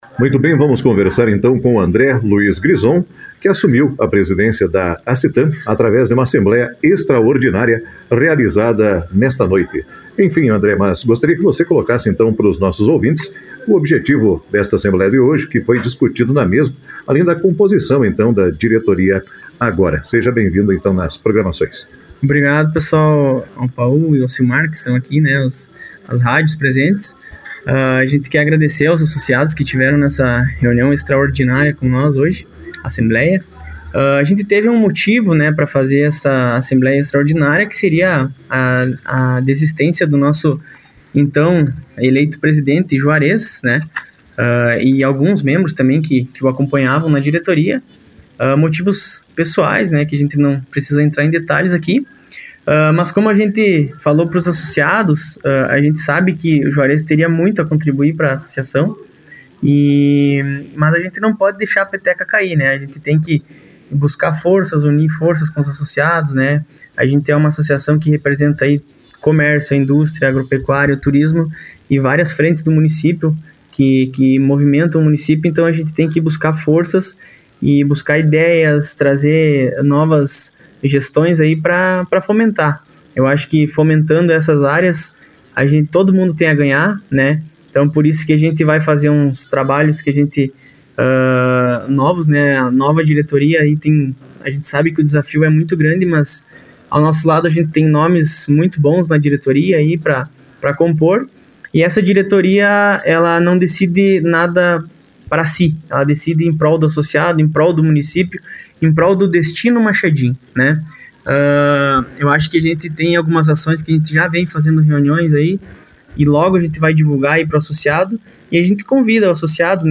Entrevista-Apos-algumas-alteracoes-assembleia-defini-nova-diretoria-da-ACITAM.mp3